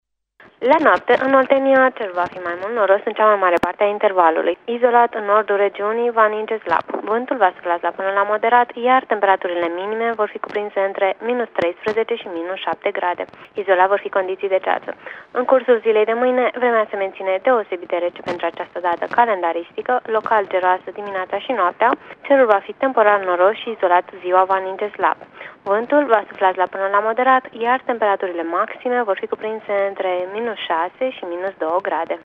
Prognoza meteo 29/30 noiembrie (audio)